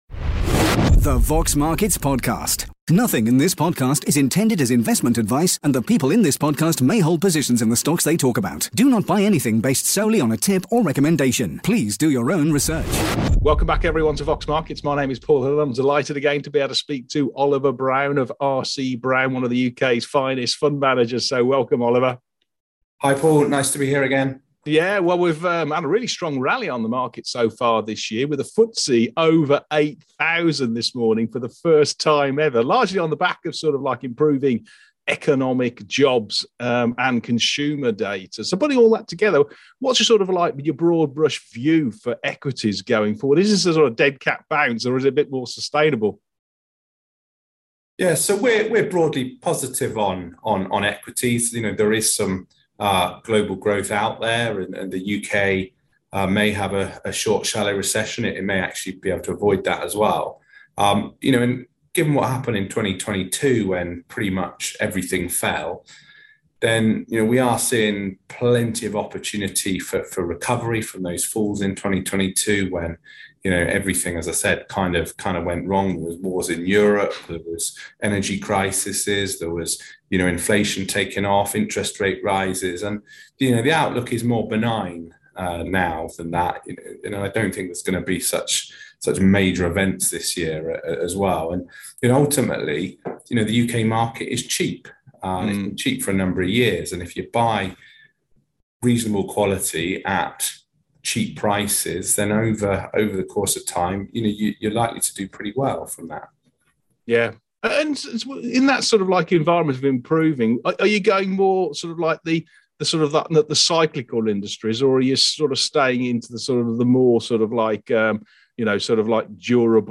Watch the Video interview